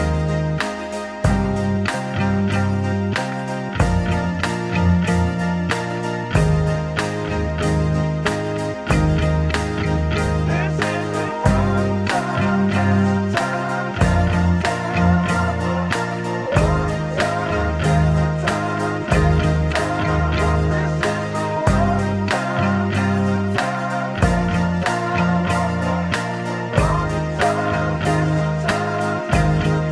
# Downtempo